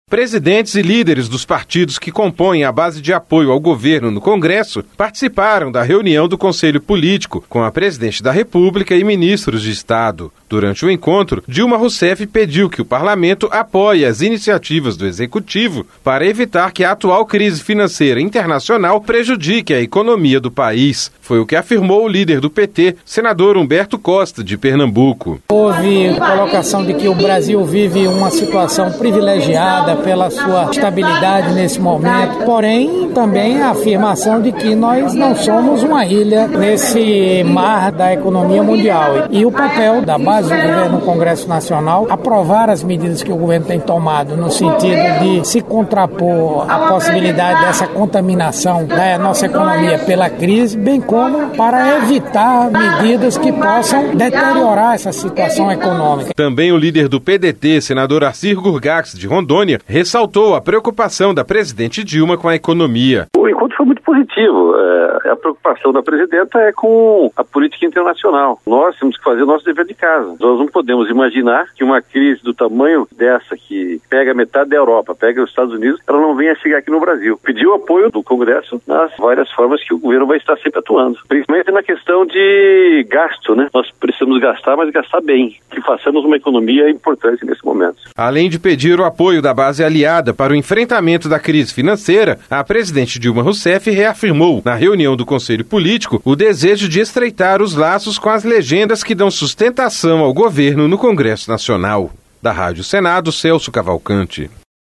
Rádio Senado
Durante o encontro, Dilma Roussef pediu que o Parlamento apoie as iniciativas do Executivo para evitar que a atual crise financeira internacional prejudique a economia brasileira. Foi o que afirmou o líder do PT, senador Humberto Costa, de Pernambuco.